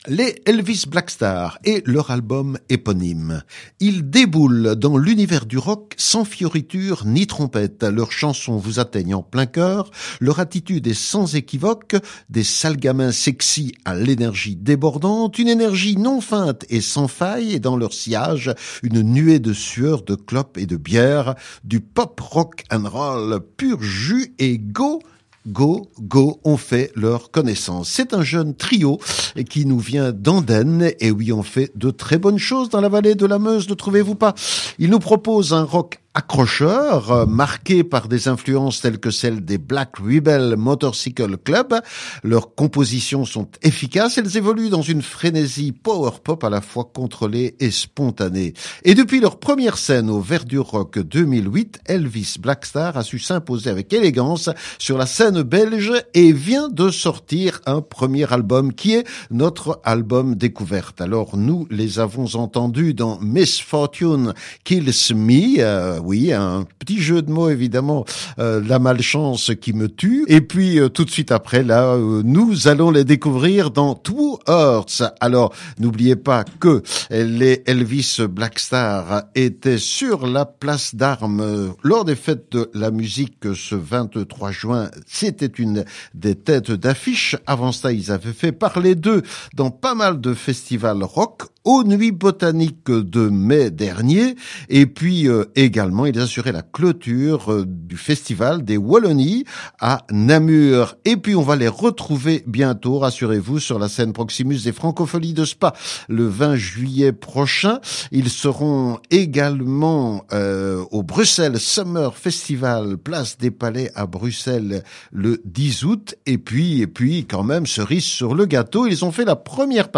Leur album est bourré de hits pop, directs et puissants.
Une énergie non feinte et sans faille.
Du pop-rock’n’roll pur jus !